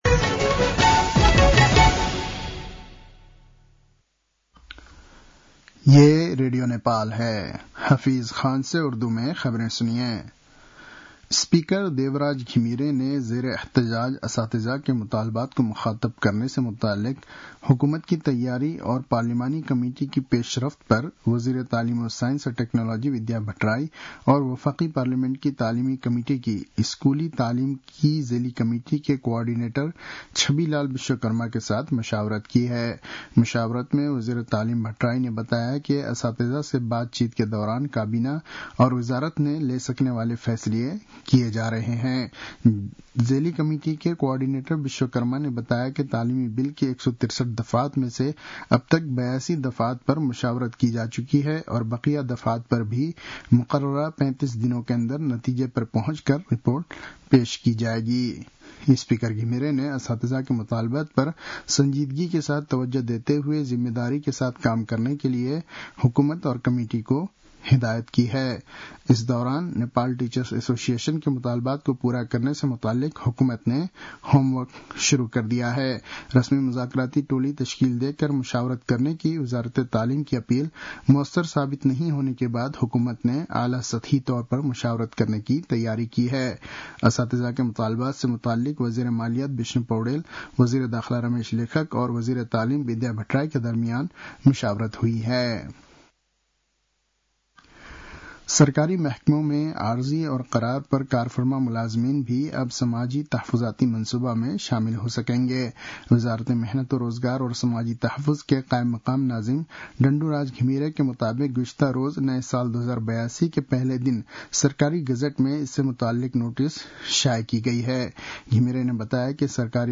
उर्दु भाषामा समाचार : २ वैशाख , २०८२